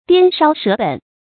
掂梢折本 注音： ㄉㄧㄢ ㄕㄠ ㄕㄜˊ ㄅㄣˇ 讀音讀法： 意思解釋： 墊錢虧本。